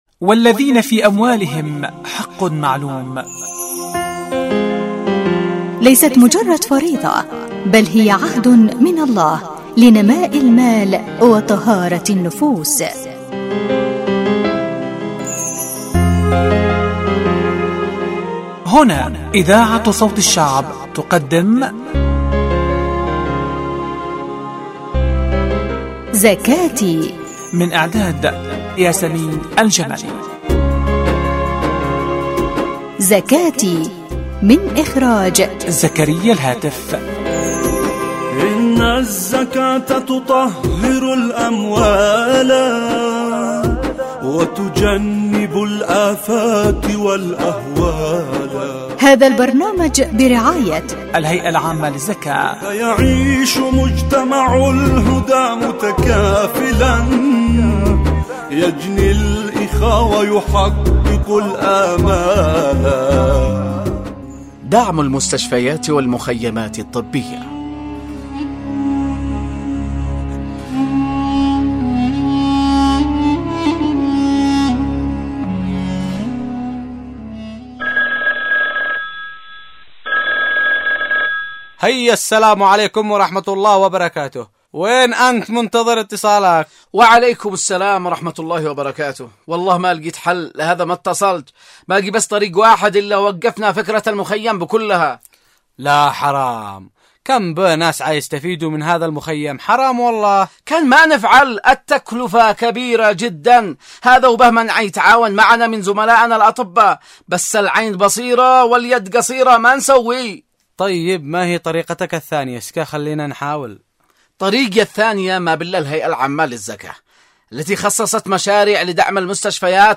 البرامج الحوارية